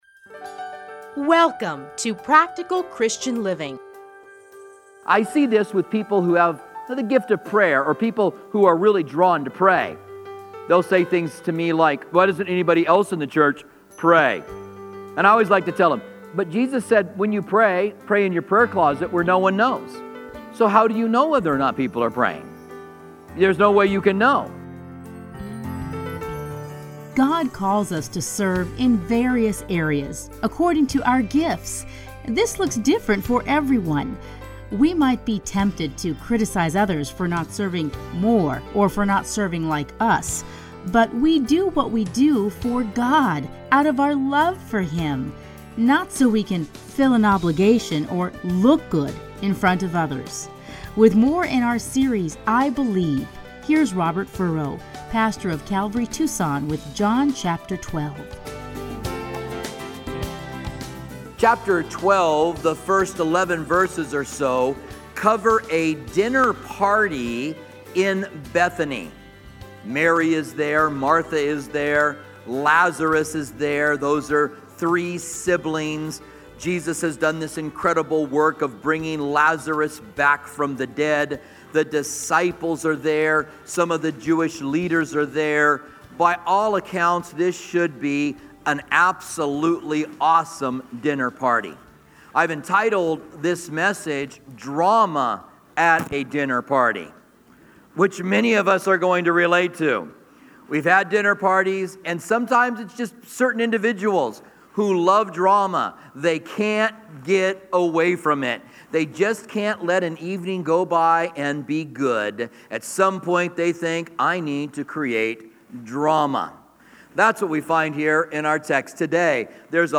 teachings are edited into 30-minute radio programs titled Practical Christian Living.